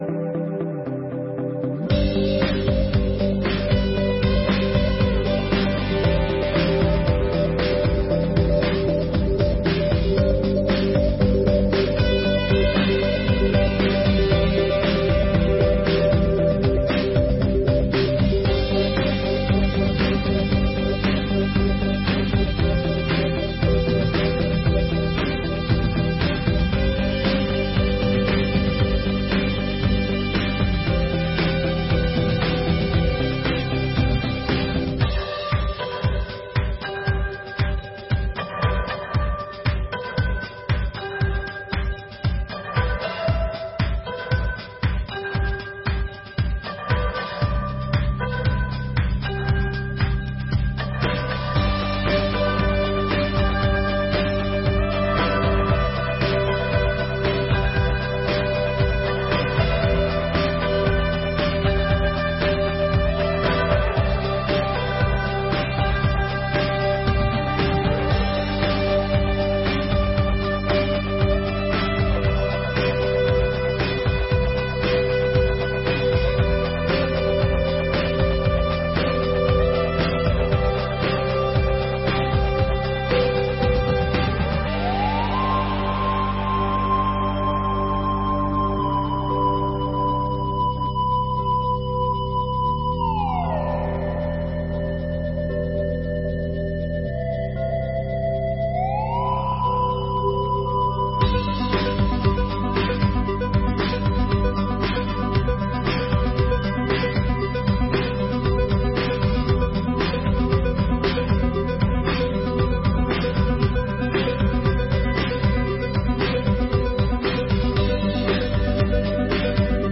Sessões Solenes de 2023